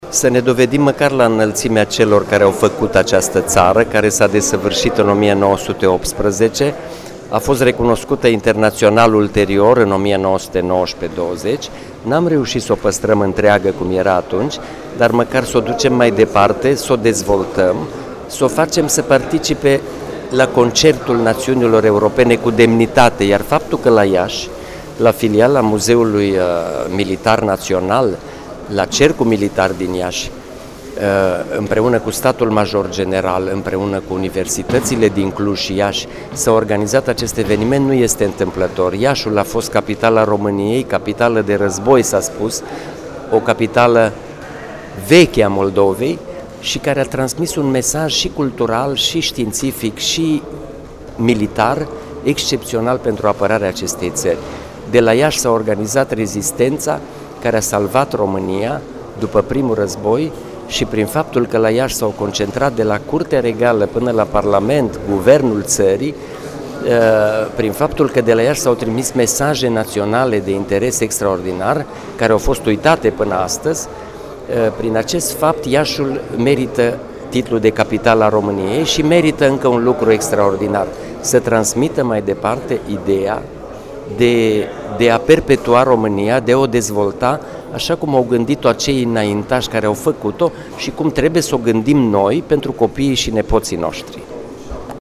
Prezent la deschiderea oficiala a evenimentului, Preşedintele Academiei Române, academician IOAN-AUREL POP sublinia faptul că in continuare românii, beneficiind de exemplul și de înfăptuirile înaintașilor, au de luptat pentru a păstra unitatea de neam și de țară.